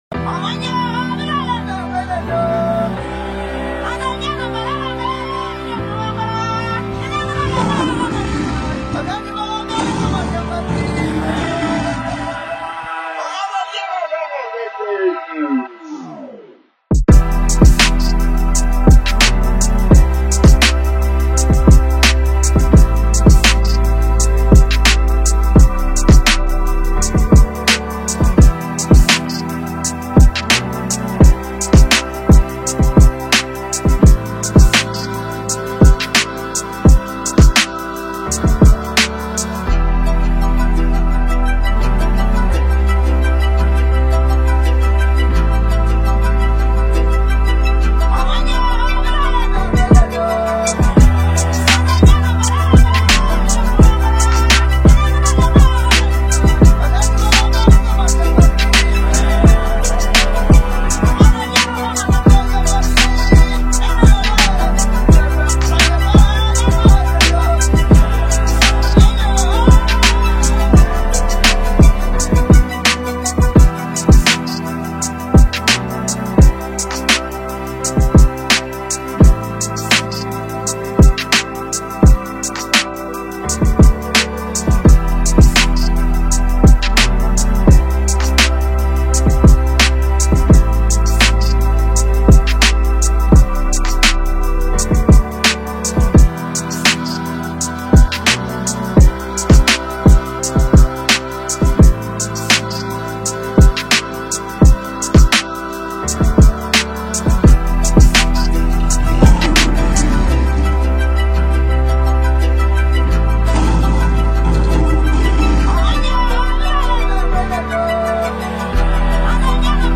powerful Tanzanian hip-hop instrumental